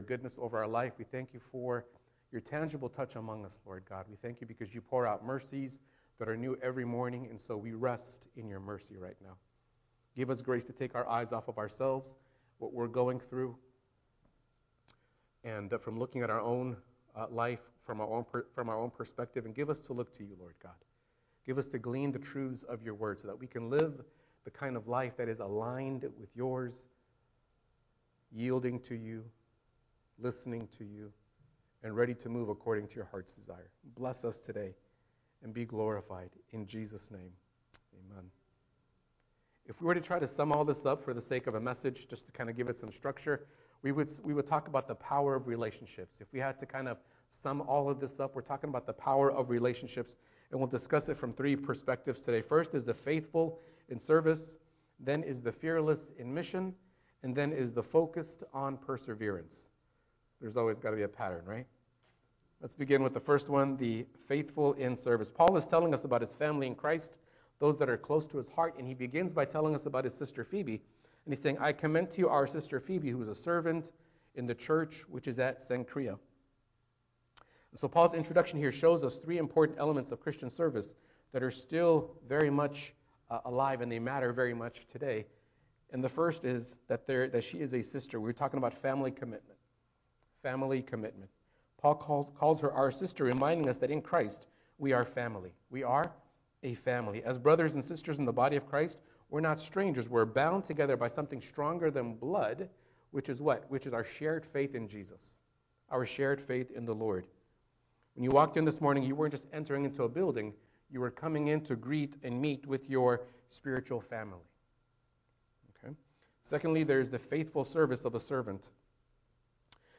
Christian sermon – God’s photo album – Arise International Church